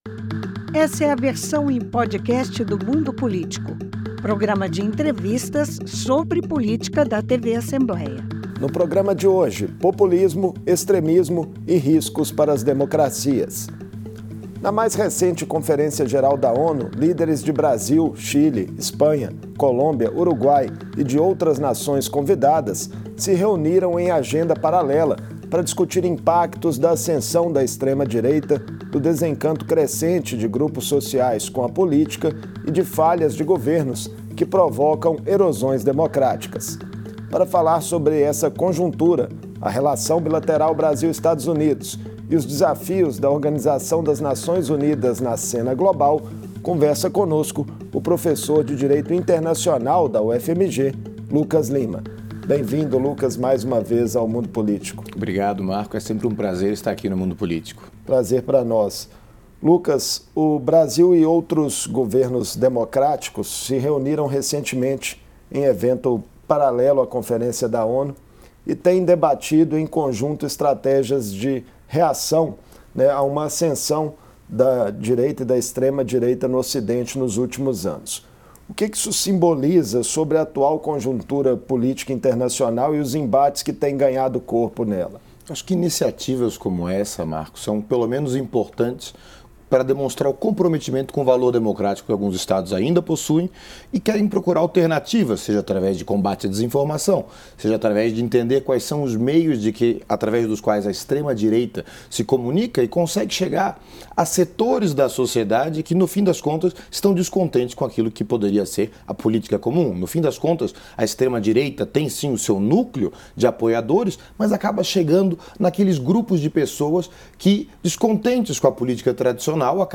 Em reunião paralela à Assembleia Geral da ONU, líderes de Chile, Colômbia, Espanha, Brasil e Uruguai discutiram a sobrevivência das democracias e o avanço de uma direita radical no mundo. Em entrevista